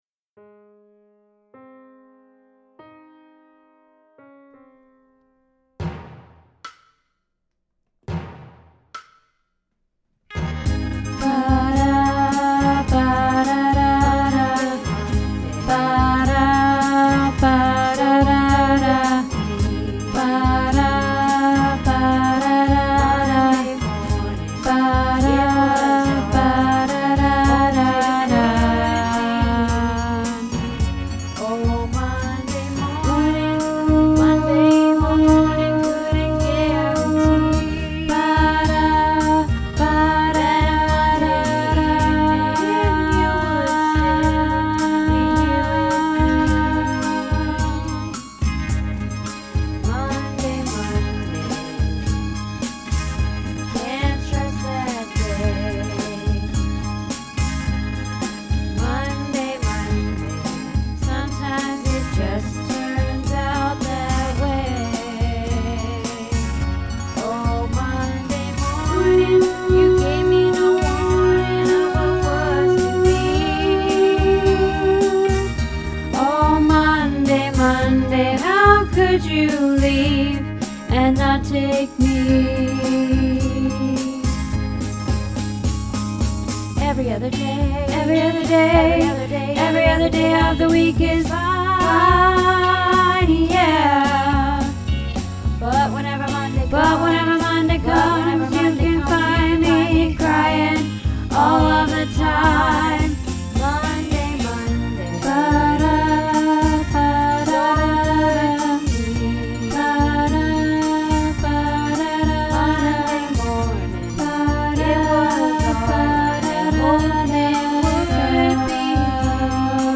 Monday Monday - Tenor